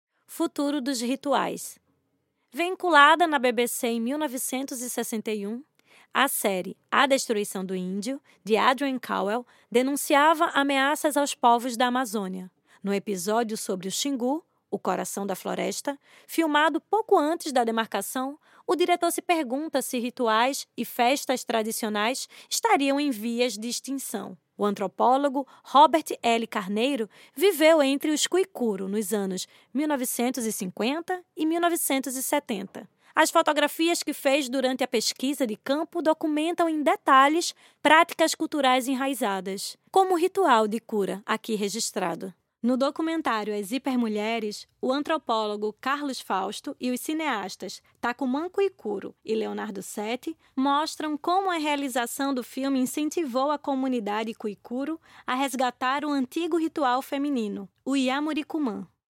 Audiodescrição